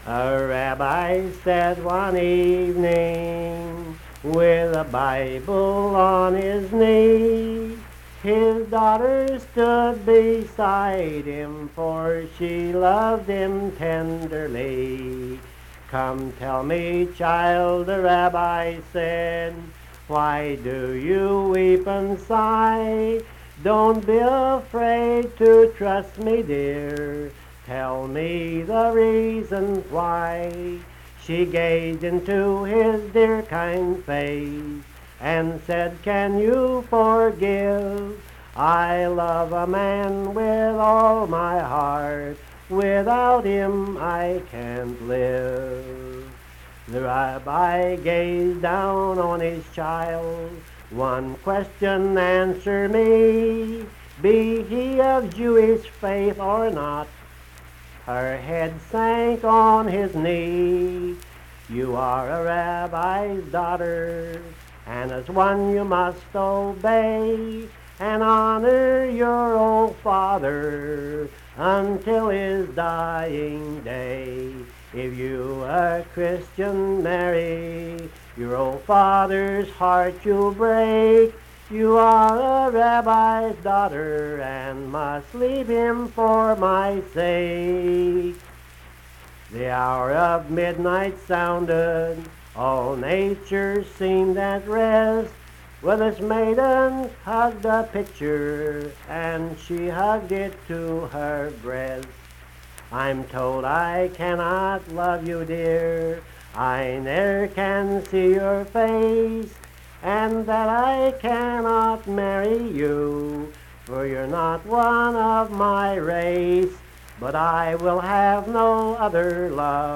Unaccompanied vocal music
Verse-refrain 5(8). Performed in Hundred, Wetzel County, WV.
Voice (sung)